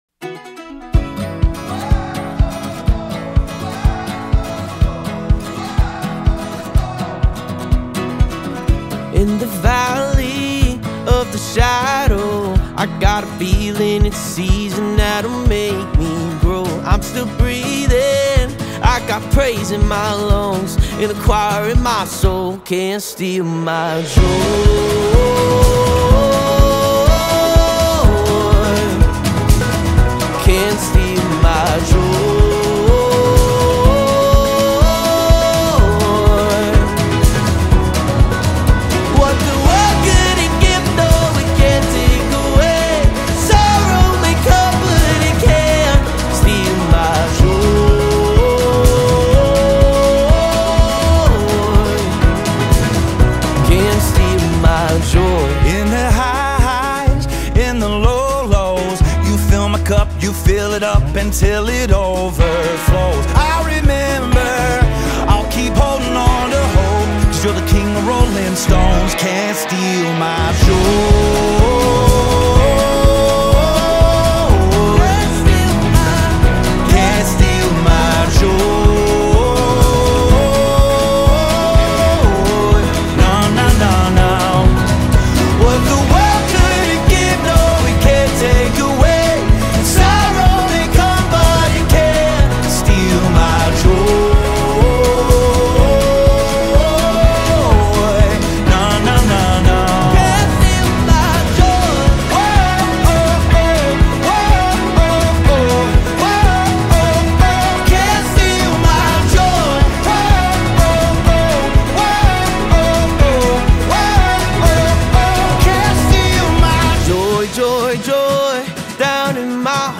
Genre: Gospel/Christian